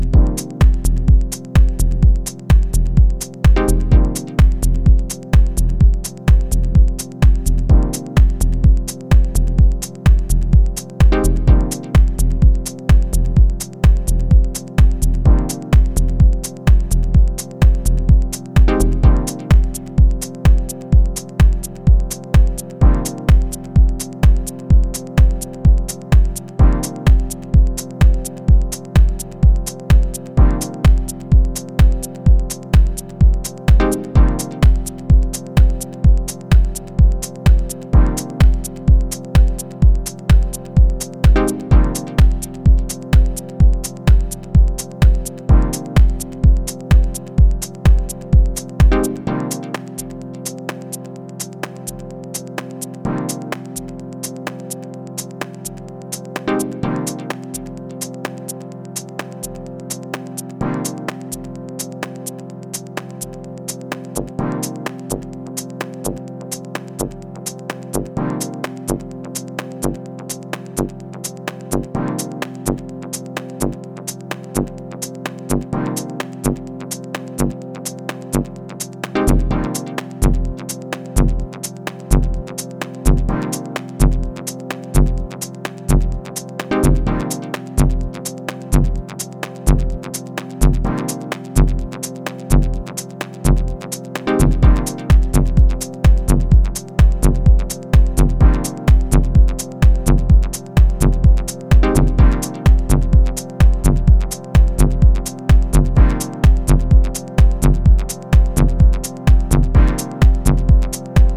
blending analog, organic, and electronic sounds